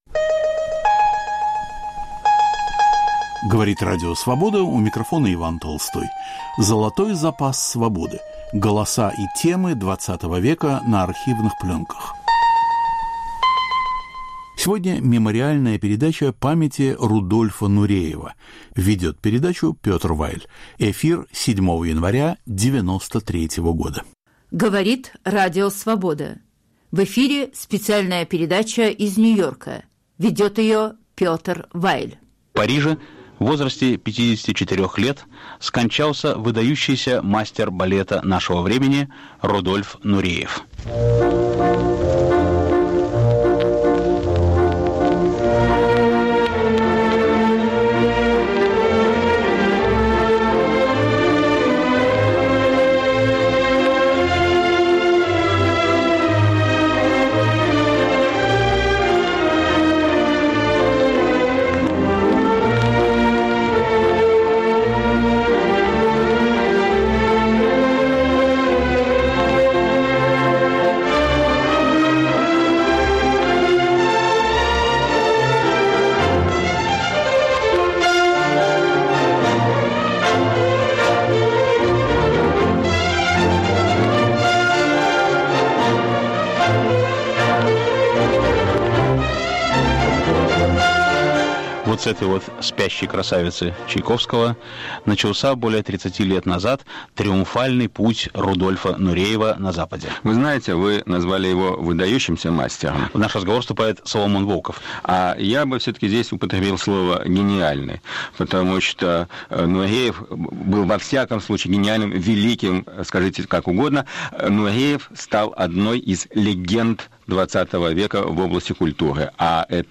Вспоминая легендарного танцовщика XX века. Передача из Нью-Йорка. Ведущий Петр Вайль, участвует Соломон Волков.
Беседа с Владимиром Маканиным.